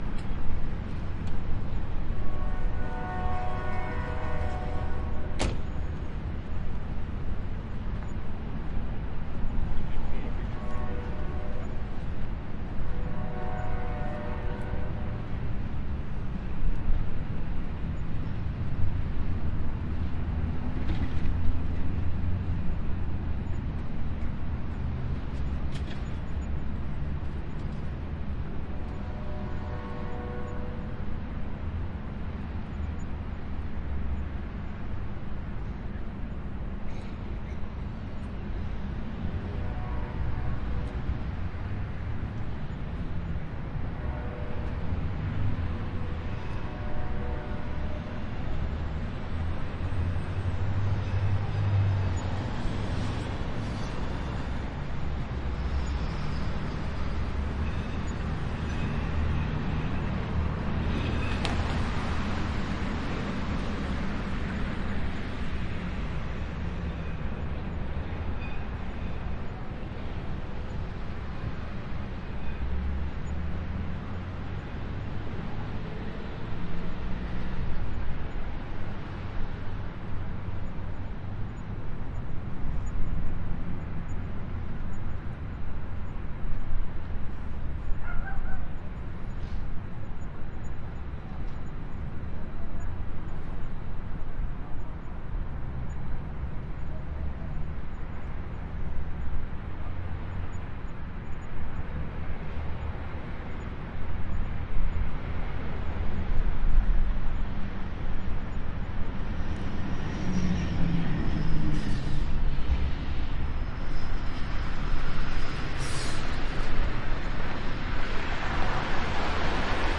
蒙特利尔 " 街道 工业区 天际线 交通 远处的火车 加拿大蒙特利尔
描述：街道工业区地平线交通遥远的火车蒙特利尔，Canada.flac
Tag: 交通 悠远 街道 天际线 火车 区域 工业